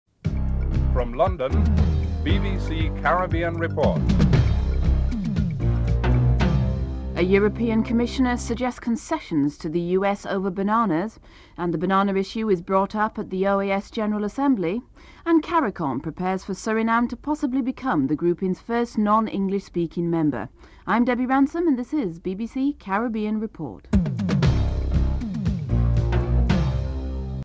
1. Headlines